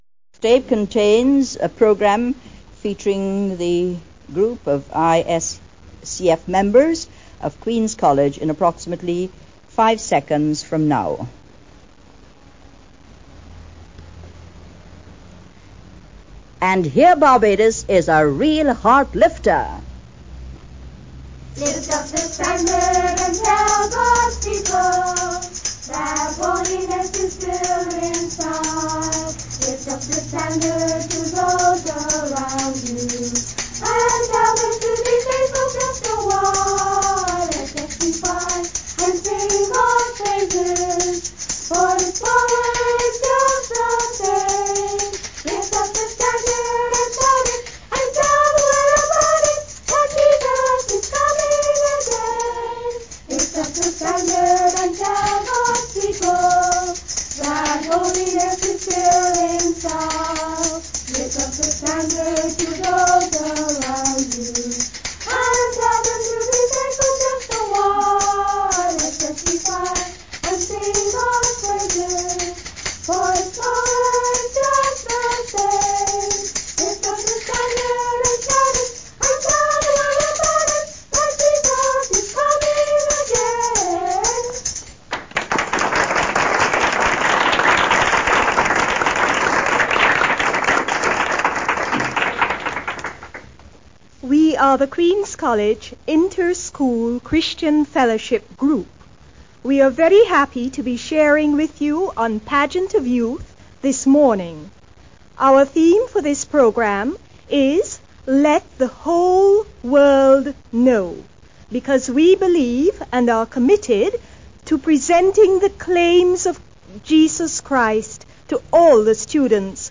Children's Party Pageant of Youth ISCF Queens College 6.4.82 Sample